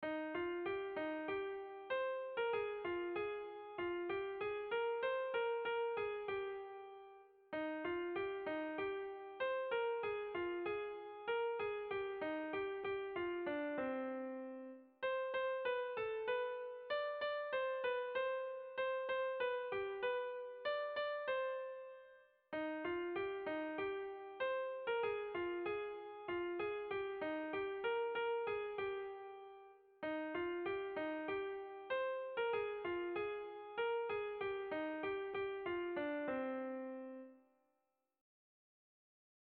Tragikoa
Zortziko handia (hg) / Lau puntuko handia (ip)
A1A2BA2